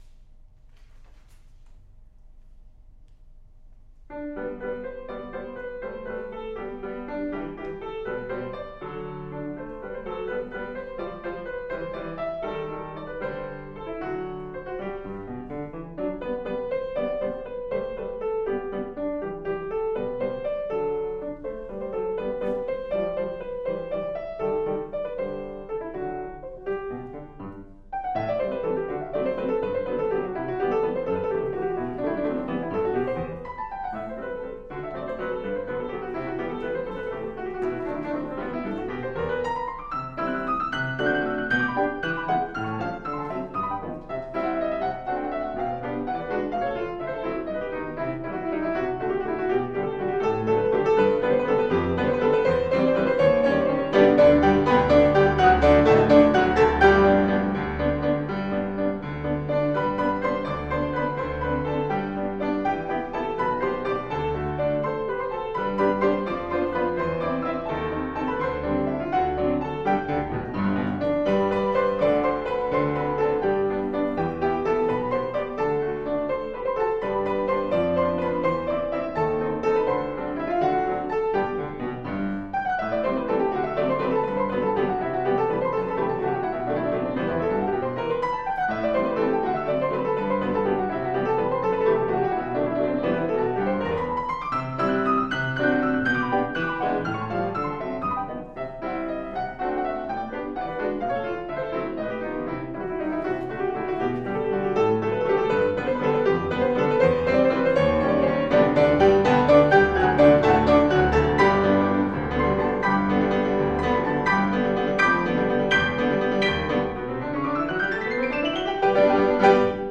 Love Story for Two Pianos
Duet / 2010
Movement 5: Waltz